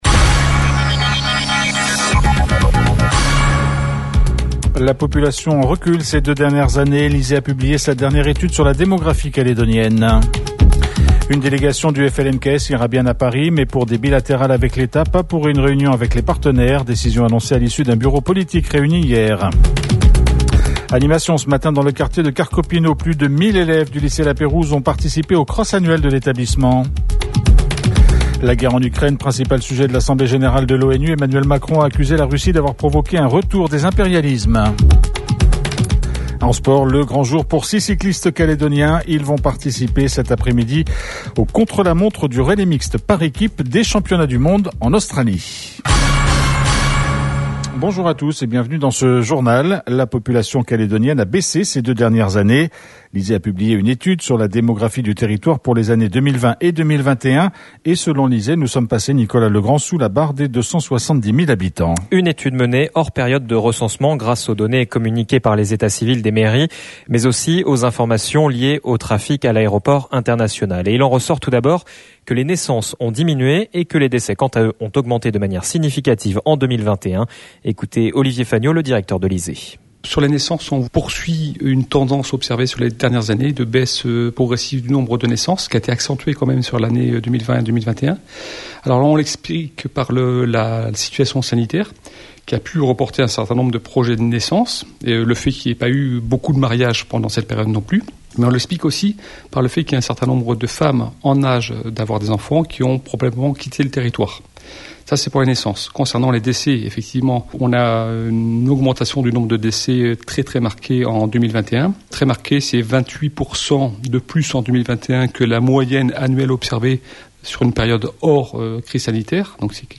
JOURNAL : MERCREDI 21 09 22 (MIDI)